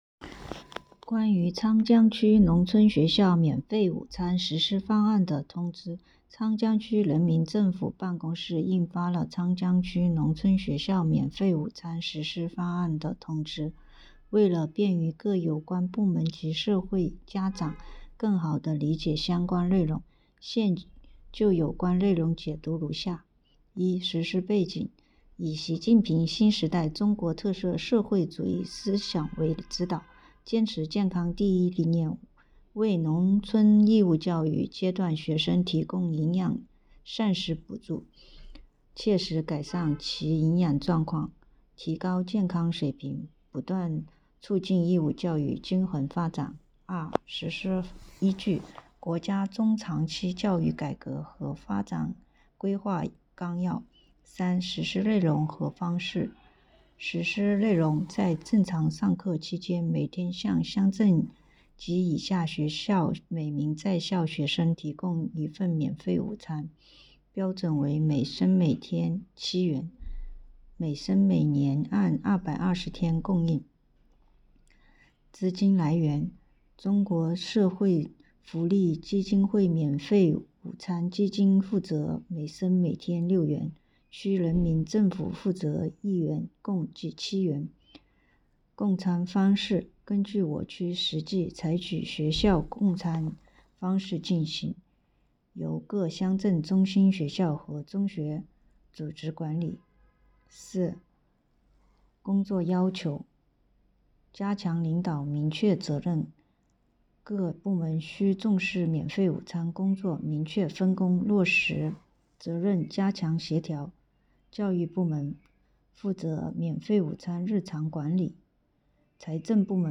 语音解读 《昌江区“农村学校免费午餐”实施方案》.mp3